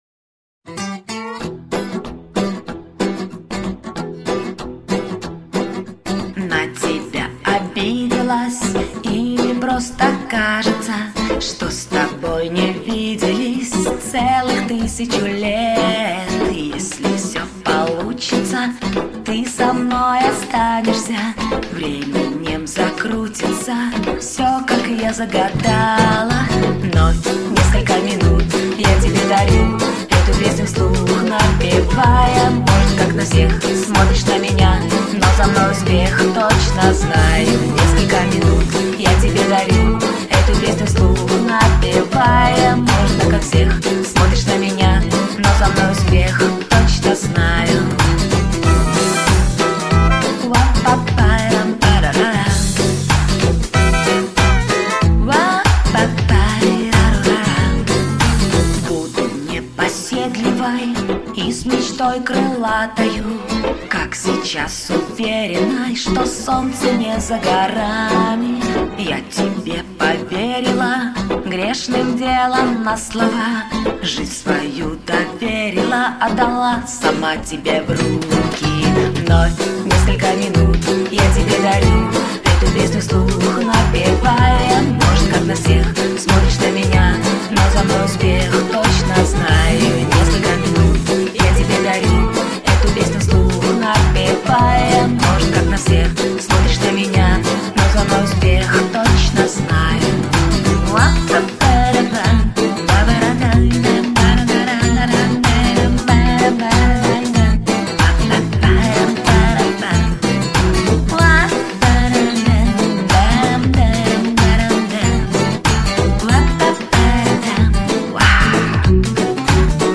вам обеим удалось передать настроение)